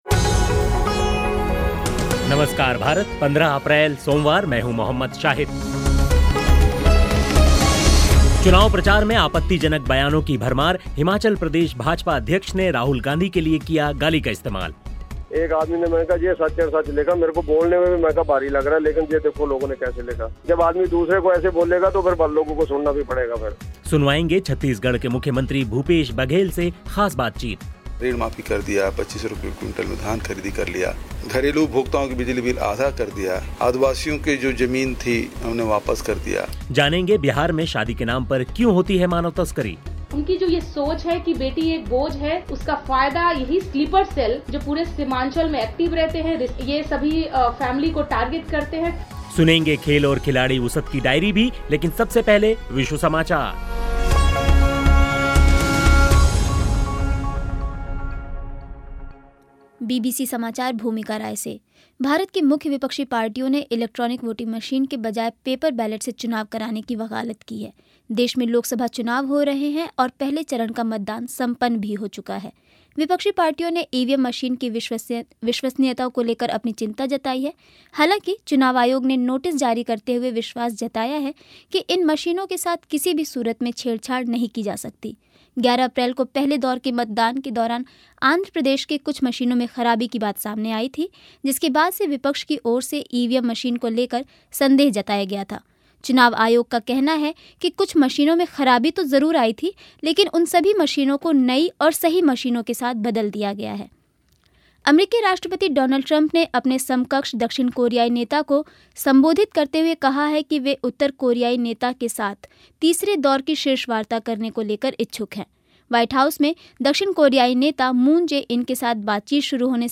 सुनवाएंगे छत्तीसगढ़ के मुख्यमंत्री भूपेश बघेल से ख़ास बातचीत.